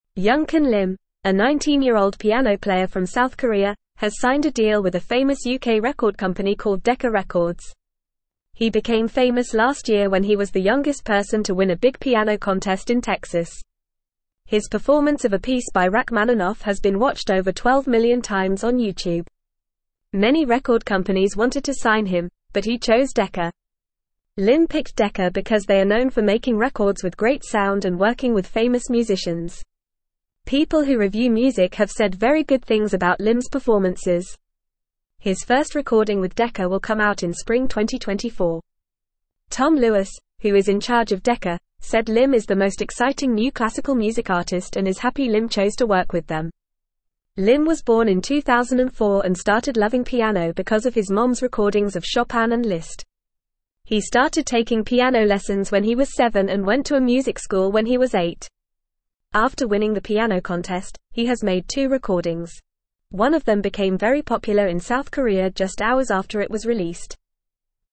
Fast
English-Newsroom-Lower-Intermediate-FAST-Reading-Young-Korean-Pianist-Signs-with-Famous-Music-Company.mp3